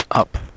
This problem deals with adaptive noise cancellation using the LMS algorithm.